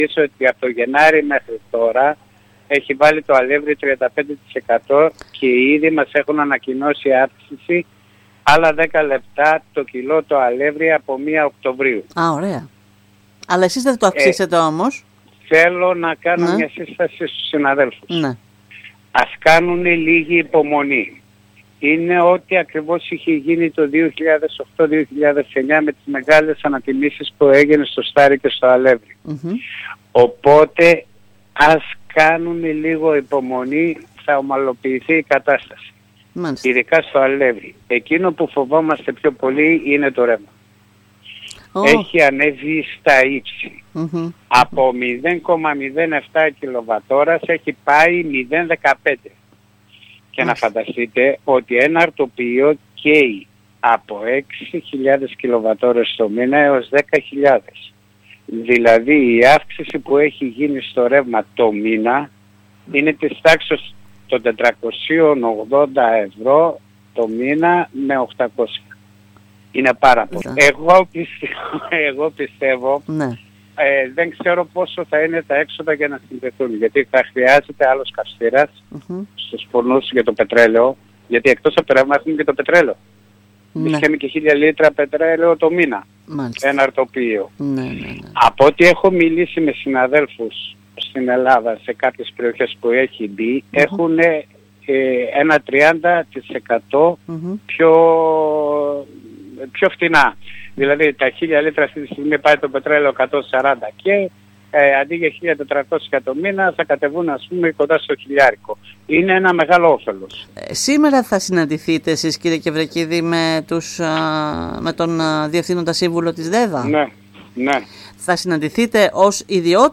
μιλώντας σήμερα στην ΕΡΤ Ορεστιάδας